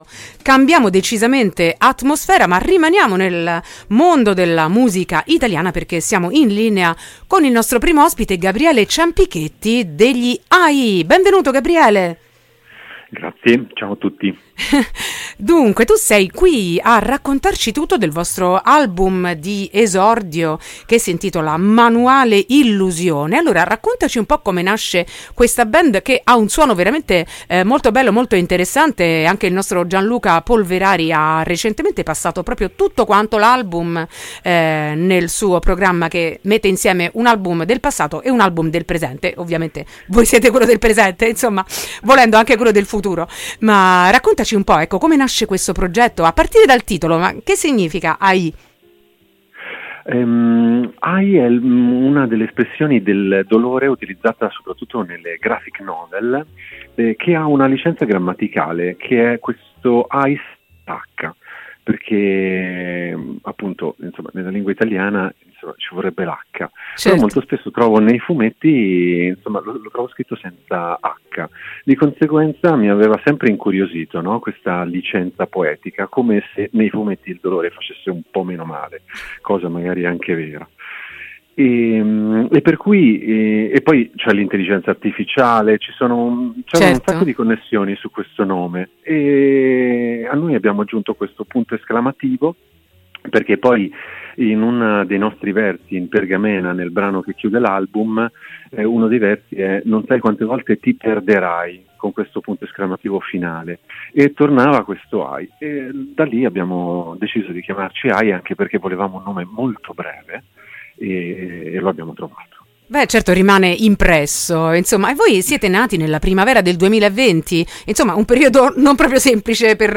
intervista-ai.mp3